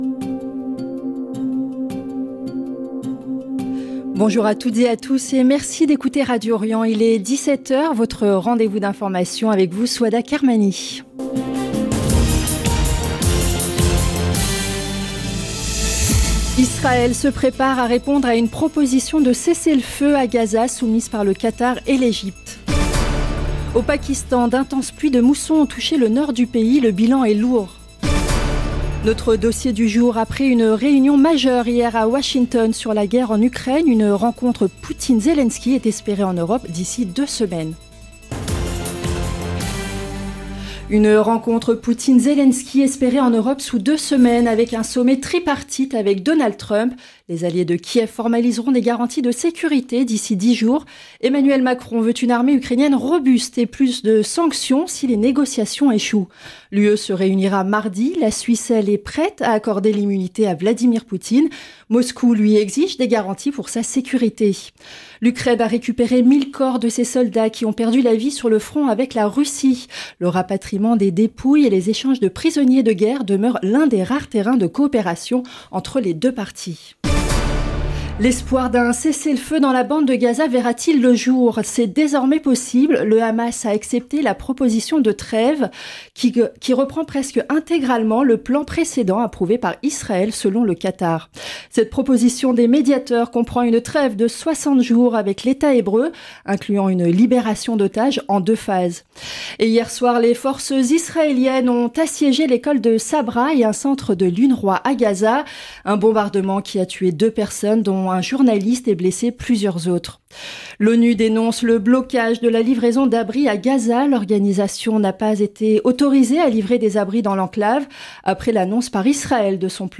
Magazine d'information du mardi 19 août 2025